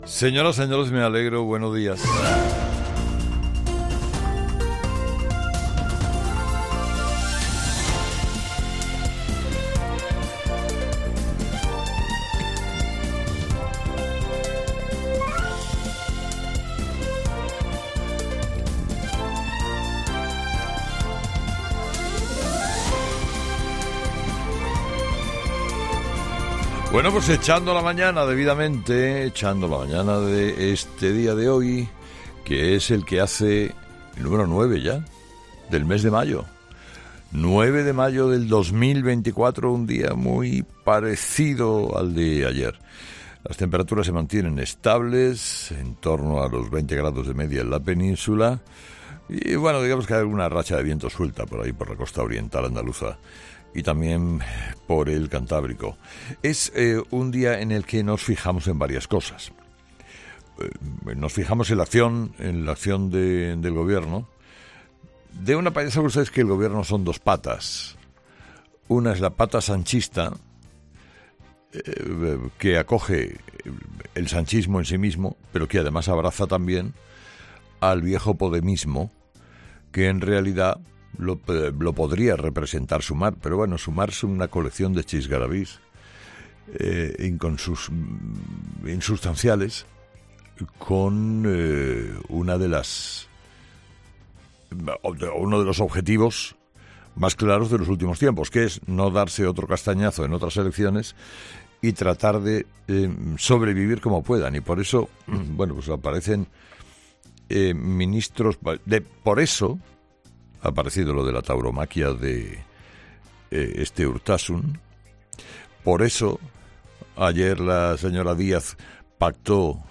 AUDIO: Escucha el análisis de Carlos Herrera a las 06:00 en Herrera en COPE del jueves 9 de mayo